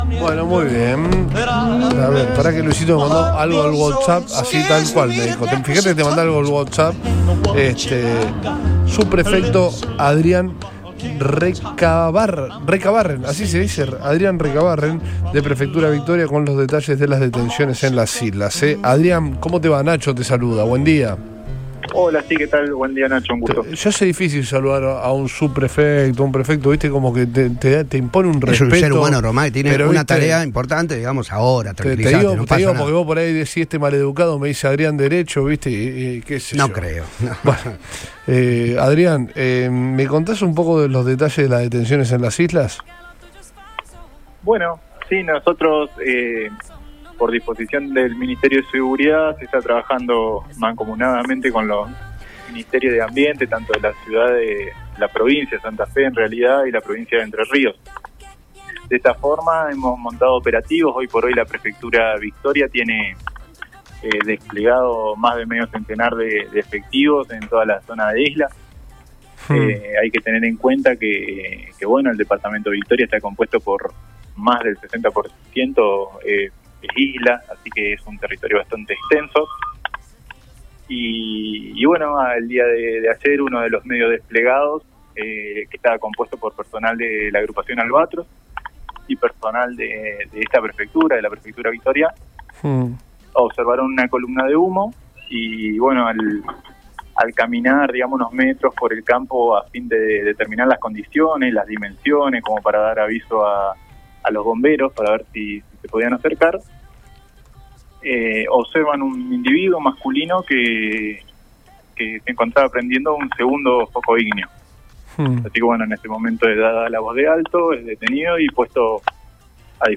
EN RADIO BOING